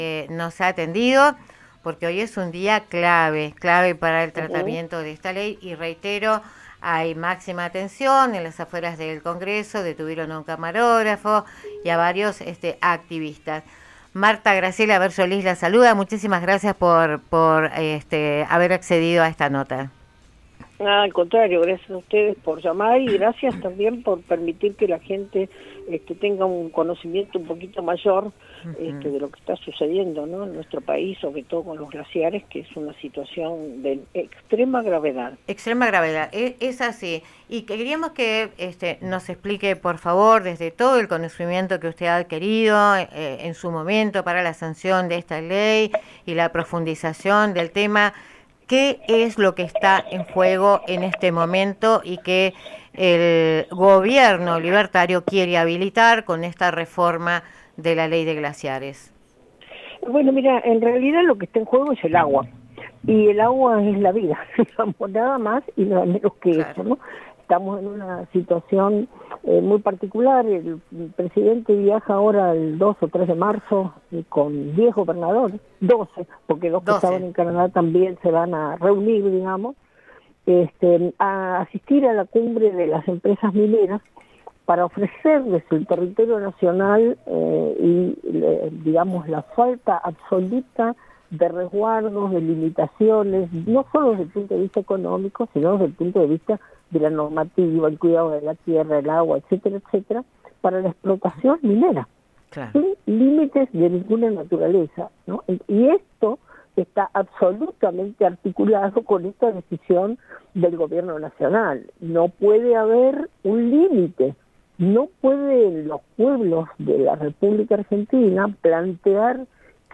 Entrevista a Lorena Matzen, legisladora UCR. 03 de marzo 2026